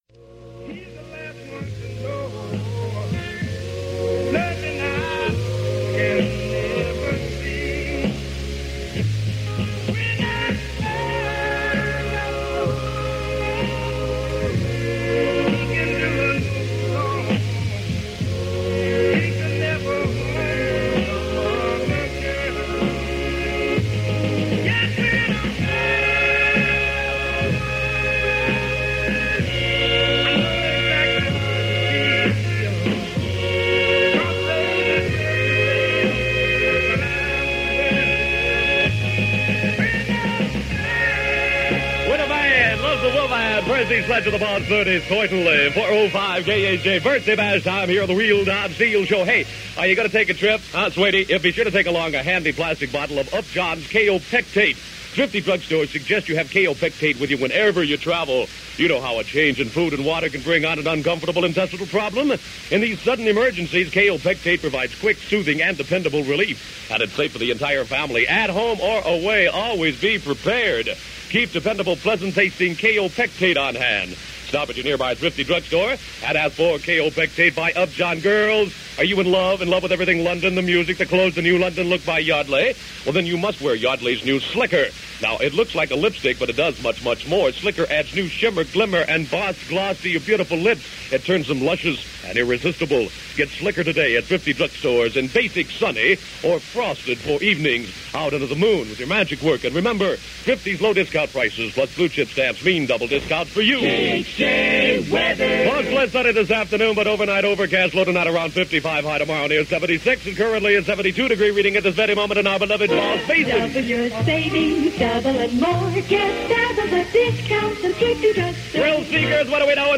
It's April 1966 - You're A Teenager - You Live In L.A. - You're In High School - You Don't Dance -Well, you do, but . . . - Past Daily Pop Chronicles